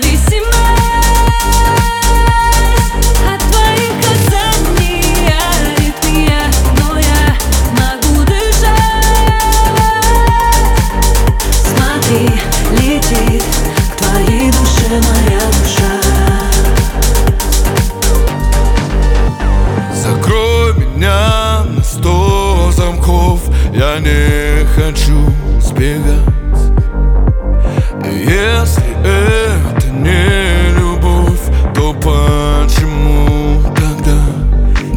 Жанр: Поп музыка / Танцевальные / Русский поп / Русские